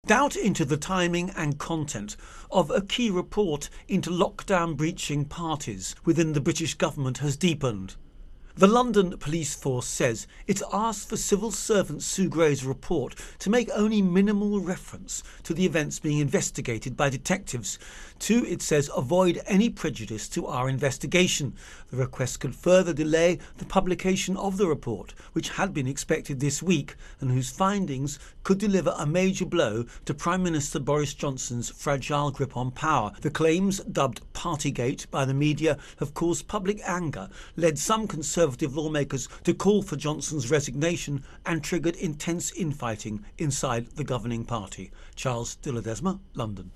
Britain-Politics Intro and Voicer